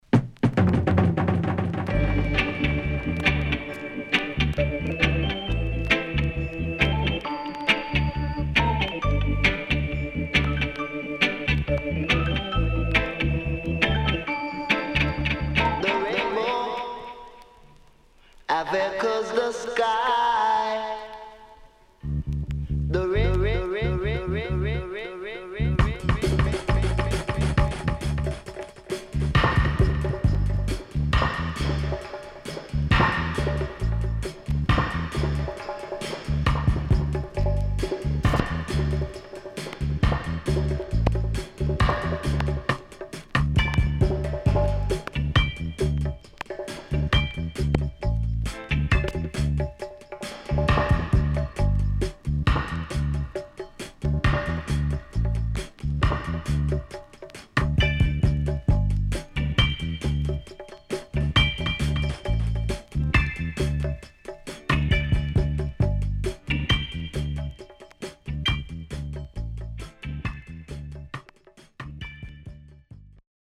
HOME > REGGAE / ROOTS  >  RECOMMEND 70's
CONDITION SIDE A:VG(OK)〜VG+
SIDE A:所々チリノイズ入ります。